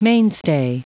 Prononciation du mot mainstay en anglais (fichier audio)
Prononciation du mot : mainstay